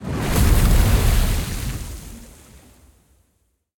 Sfx_creature_glowwhale_breach_in_01.ogg